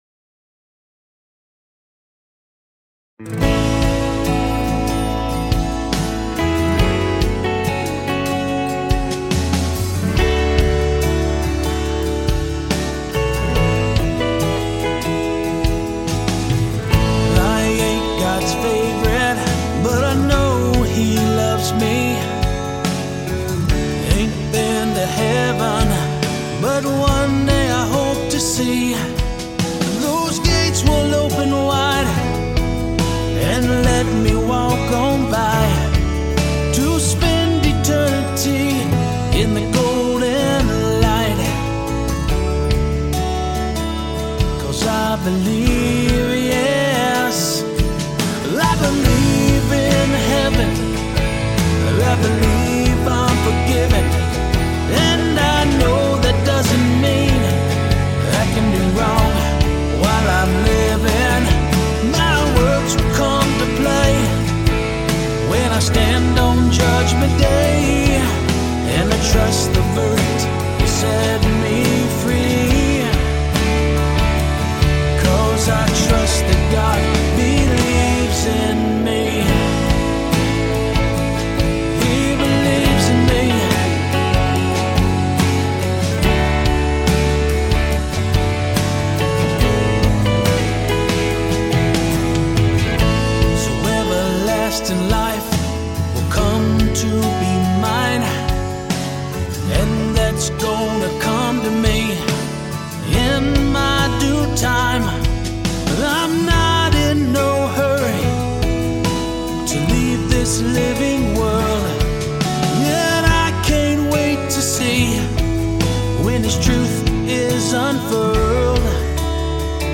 Complete Demo Song, with lyrics and music: